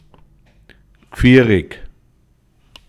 gfiahrig / Begriff-ABC / Mundart / Tiroler AT / Home - Tiroler Versicherung
praktisch, handlich, paßt genau Reith im Alpbachtal